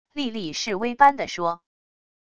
莉莉示威般的说wav音频